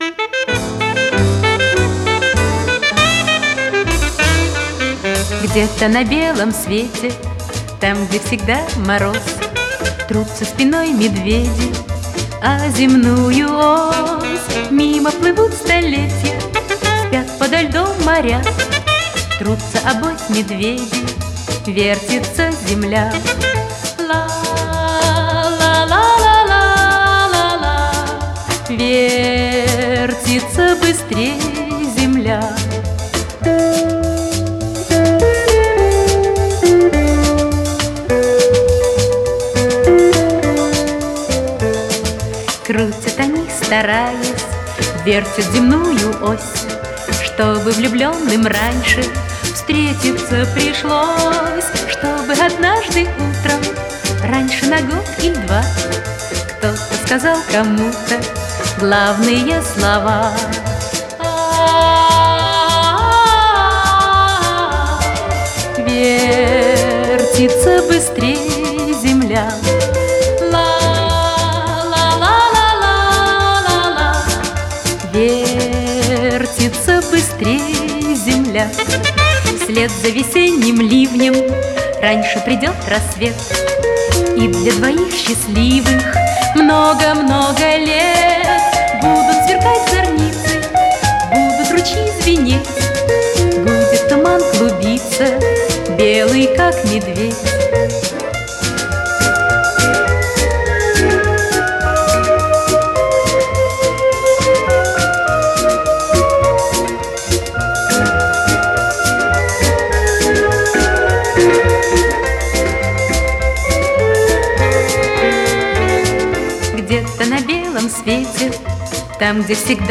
(звучит с пластинки)